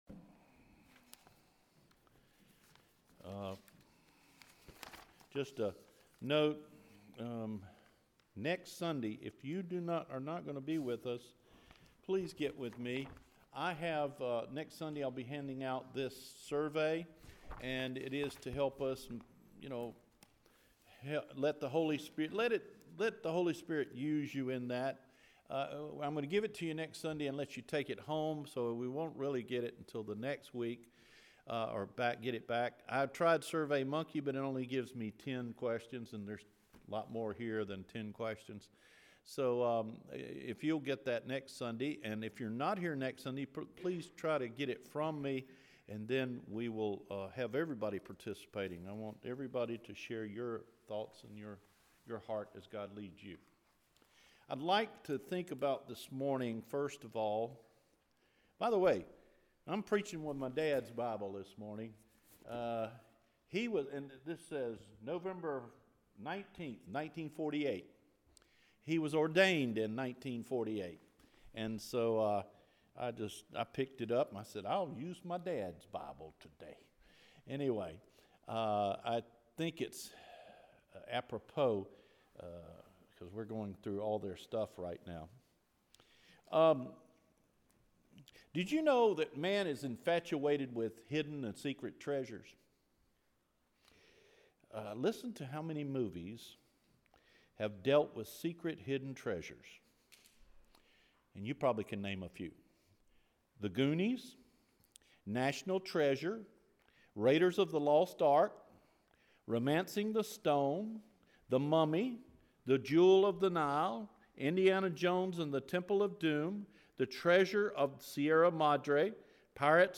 Secret Repository of Blessings – February 4 Recorded Sermon